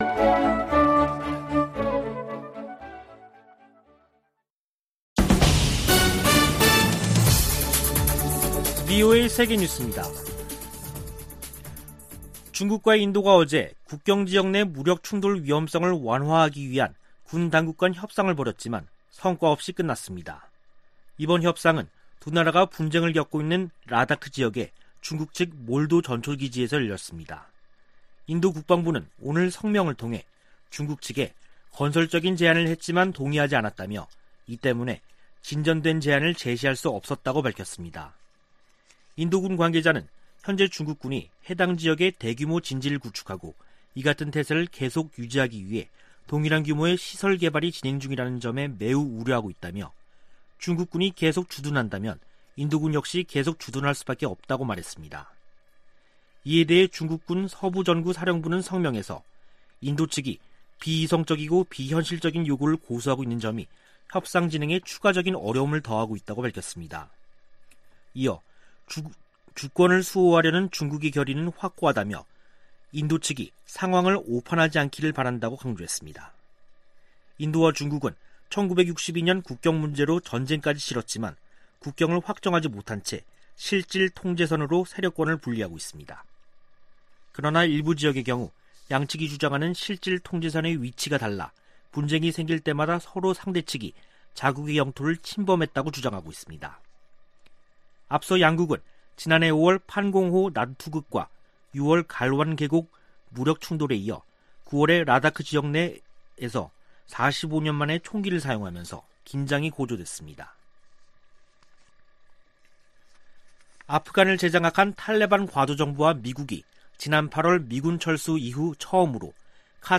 VOA 한국어 간판 뉴스 프로그램 '뉴스 투데이', 2021년 10월 11일 3부 방송입니다. 북한이 남북 통신연락선을 복원한 지 일주일이 지났지만 대화에 나설 조짐은 보이지 않고 있습니다. 미국은 지난해 정찰위성 2개를 새로 운용해 대북 정보수집에 활용하고 있다고 미 국가정찰국이 밝혔습니다. 세계 300여 개 민간단체를 대표하는 40개 기구가 10일 북한 노동당 창건 76주년을 맞아 유엔 회원국들에 공개서한을 보냈습니다.